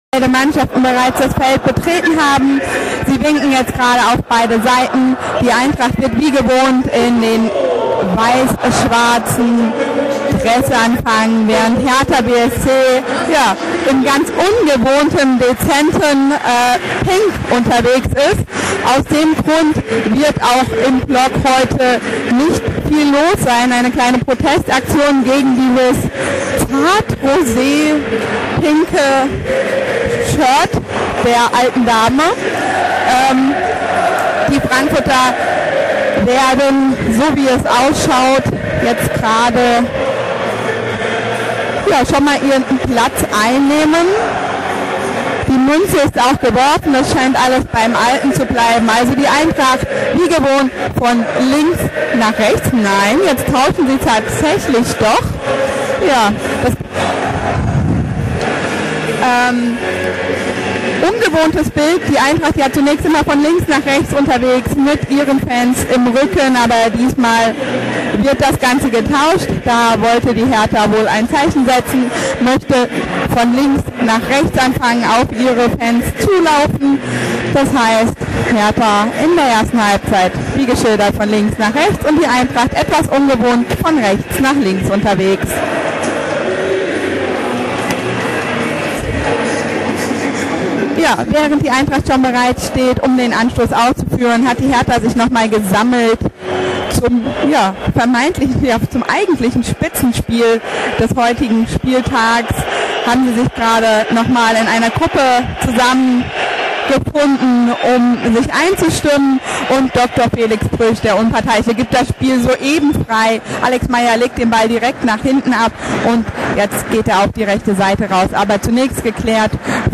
Die Spiel-Reportage im Player
Ort Commerzbank-Arena, Frankfurt